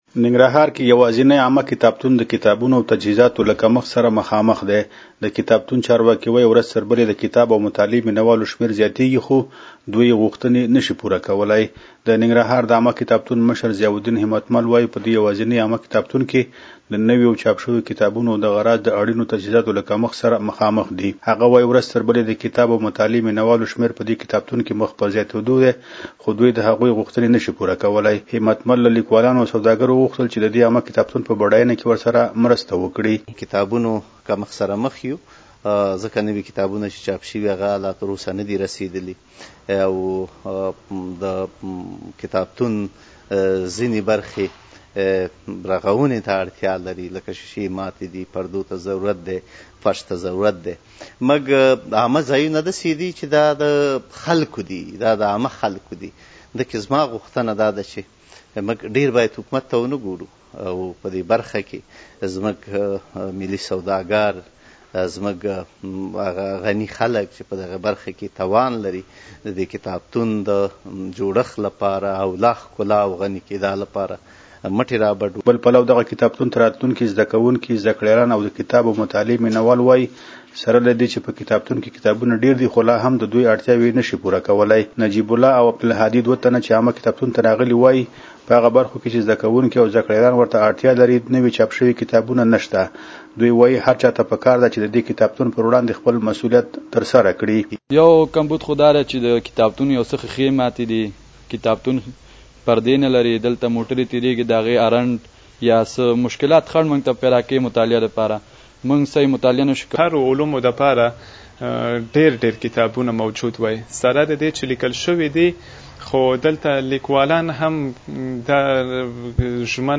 زمونږ خبریال راپور راکړی ننګرهار کې یوازینی عامه کتابتون د کتابونو او تجهیزاتو له کمښت سره مخامخ دی.